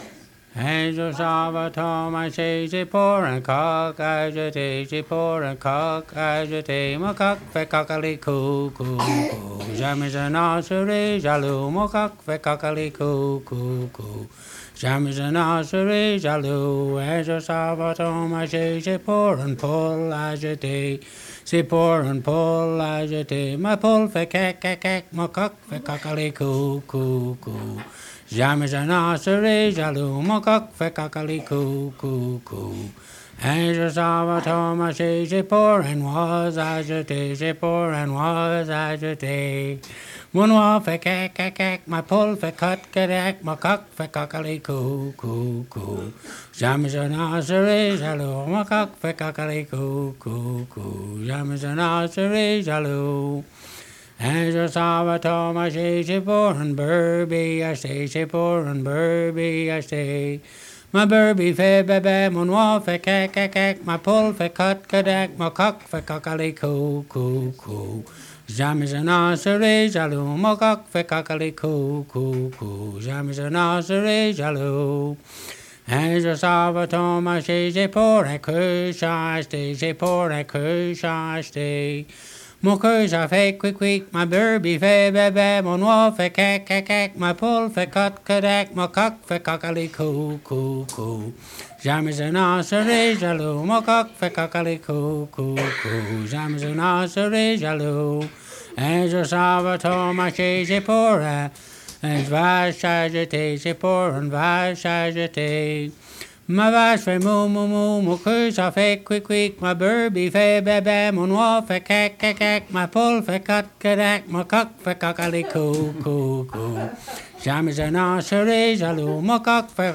Chanson Item Type Metadata
Emplacement Upper Ferry